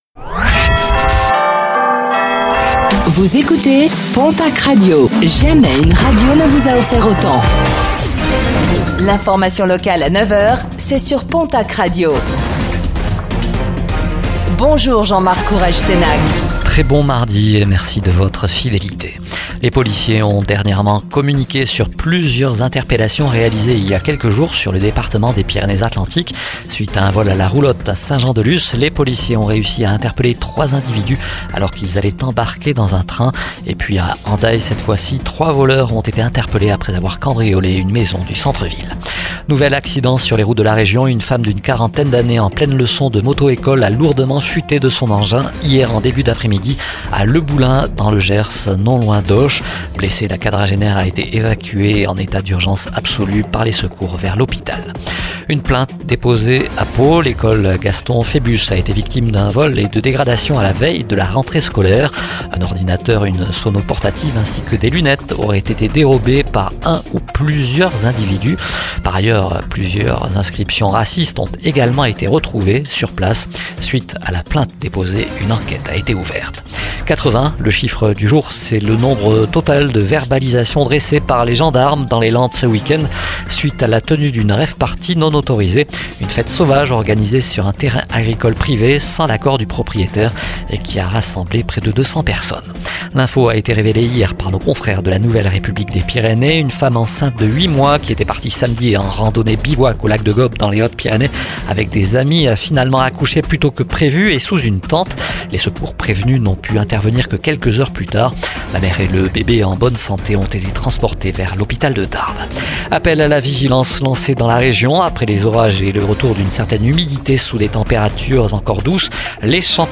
Infos | Mardi 03 septembre 2024